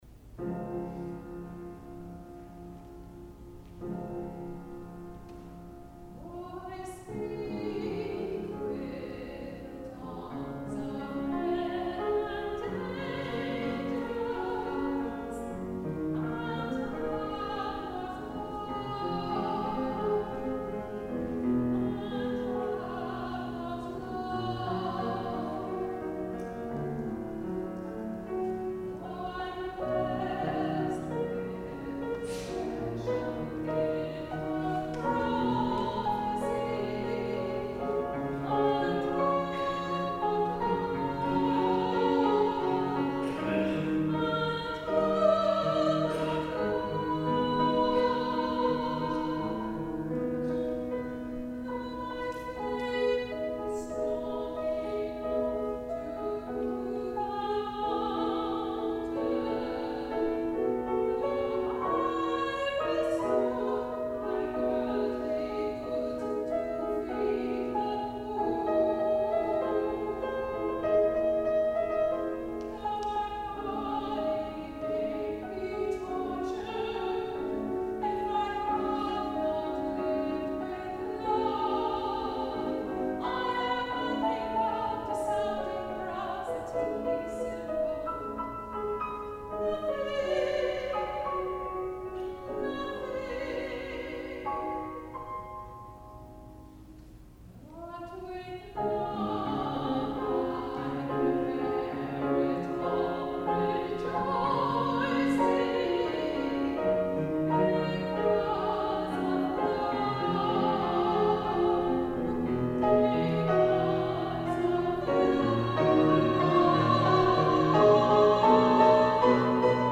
SOLO: The Greatest of These
soprano
piano